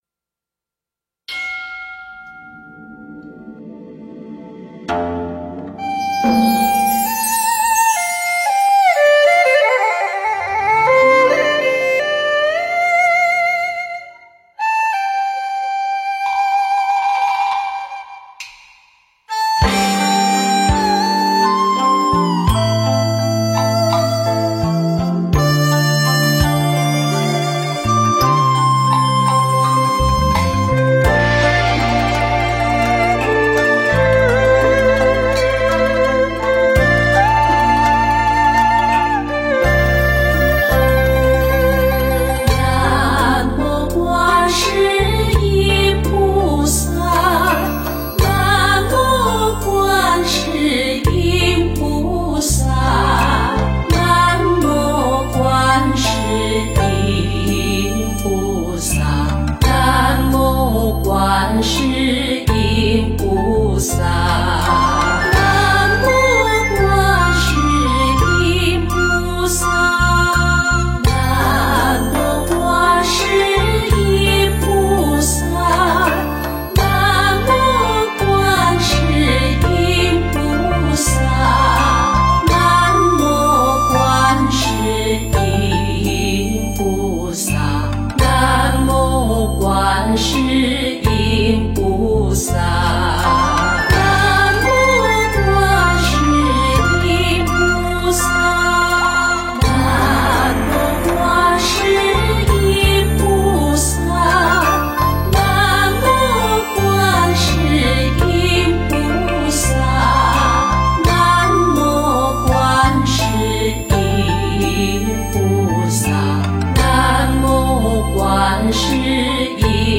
南无观世音菩萨圣号.六字大明咒.大悲咒 诵经 南无观世音菩萨圣号.六字大明咒.大悲咒--新韵传音 点我： 标签: 佛音 诵经 佛教音乐 返回列表 上一篇： 观世音菩萨圣号 下一篇： 消业障六道金刚咒(国语演唱版) 相关文章 南无大悲观世音菩萨三称 南无大悲观世音菩萨三称--如是我闻...